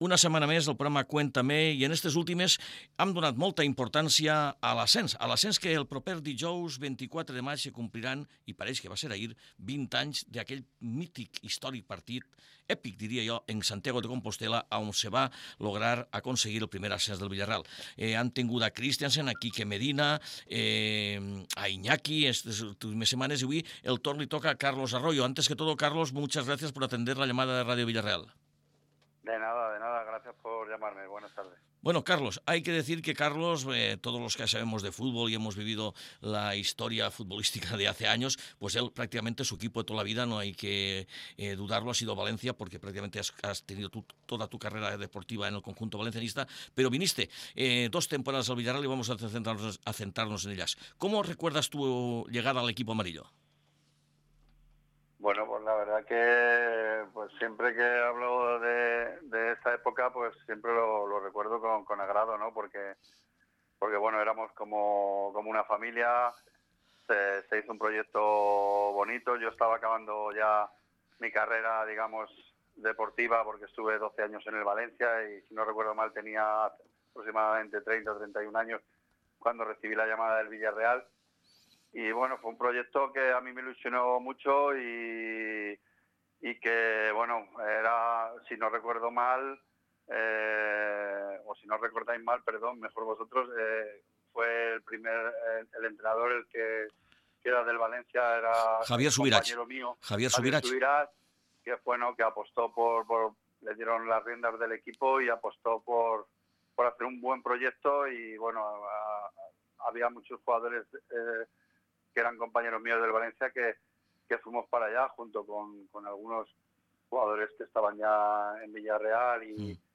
Cuéntame: entrevista